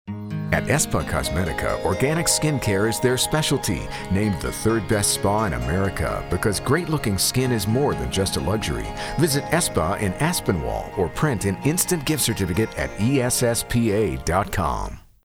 3WS - 94.5 FM Clear Channel Radio 12 Days of Christmas Promo